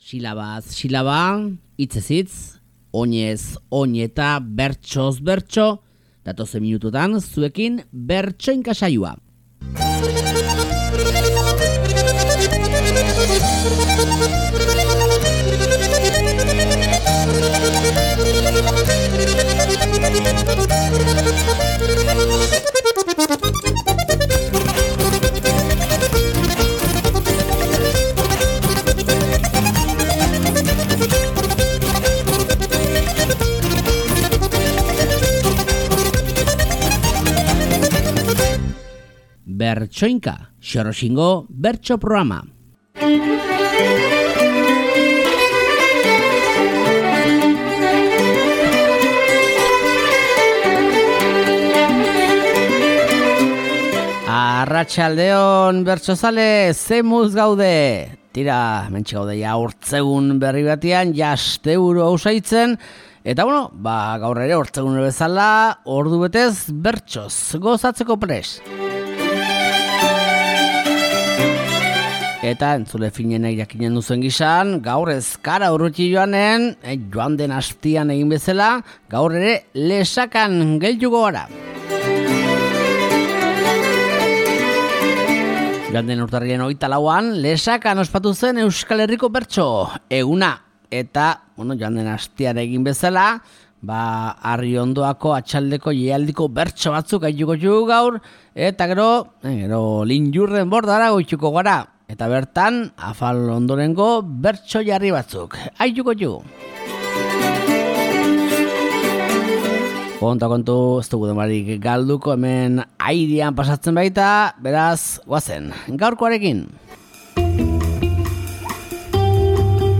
Lesakan ospatu zen Bertso eguneko bertso gehiago, aste honetako Bertsoinka saioan.